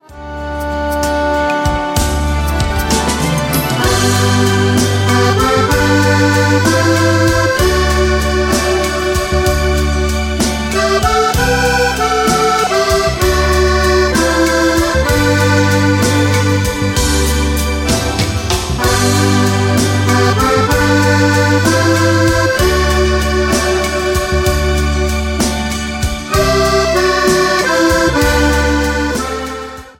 TERZINATO  (03.43)